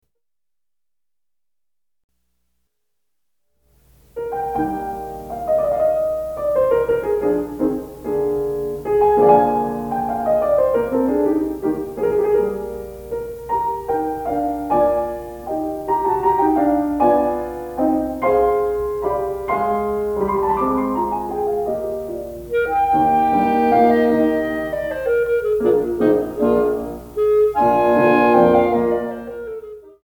クラリネット+ピアノ